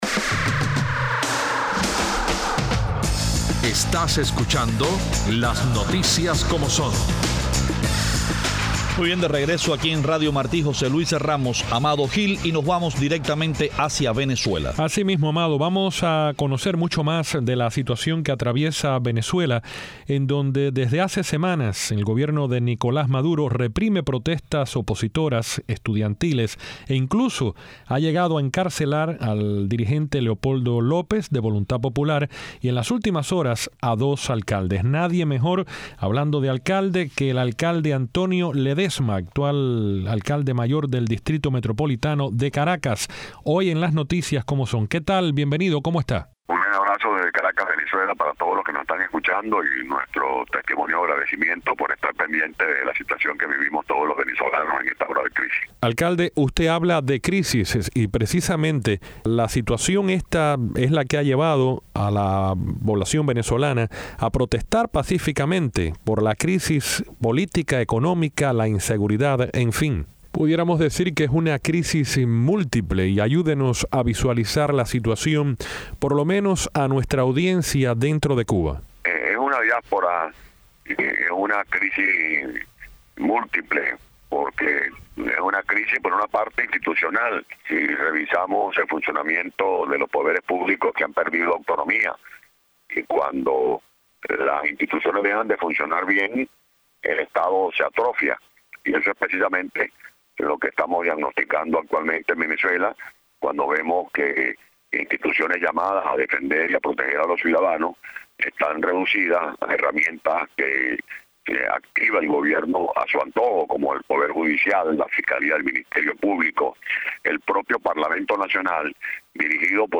Para la segunda mitad del programa el Alcalde Metropolitano de Caracas Antonio Ledezma aborda la crisis económica , política y social que afronta su país.